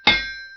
anvilhammerOrig.ogg